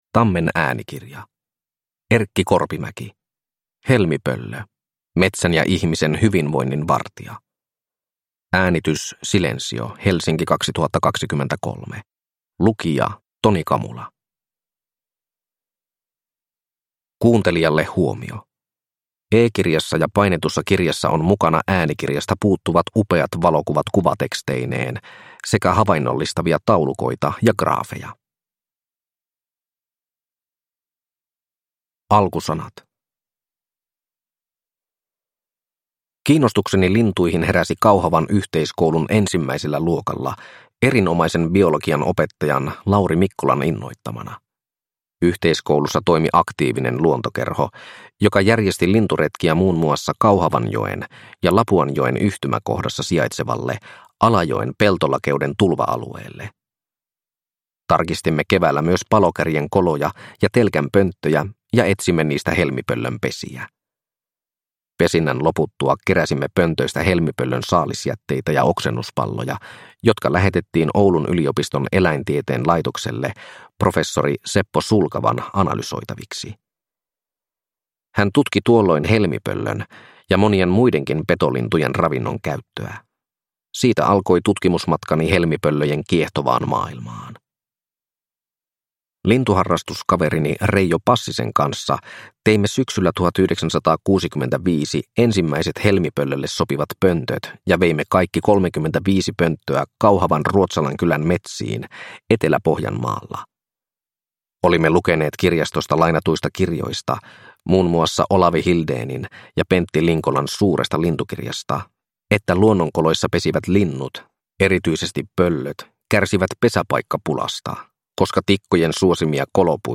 Helmipöllö – Ljudbok – Laddas ner